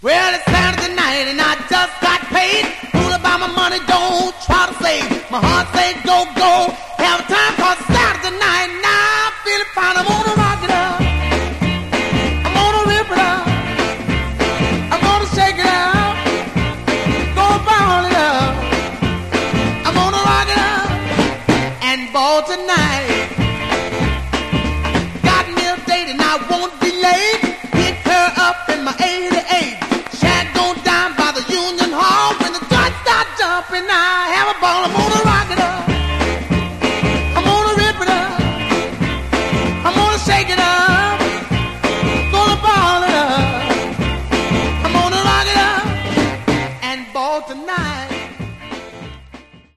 Genre: Rock 'n' Roll